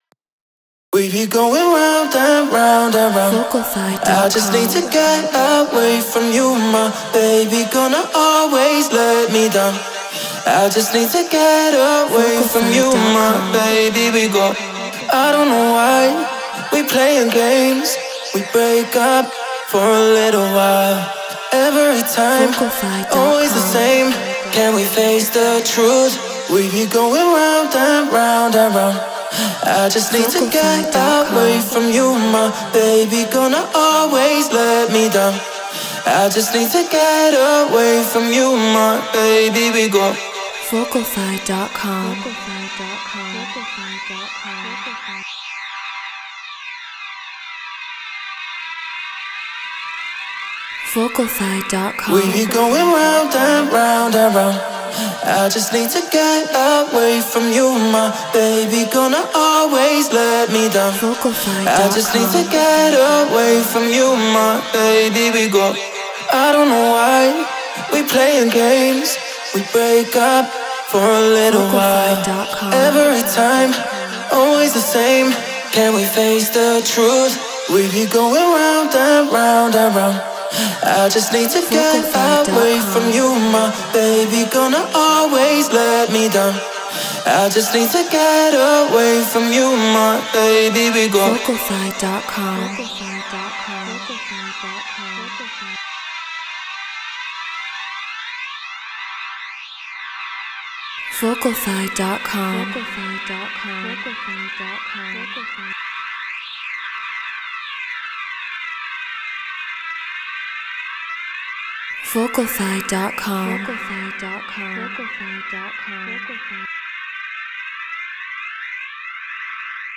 Hard Dance / Hypertechno 150 BPM Gmin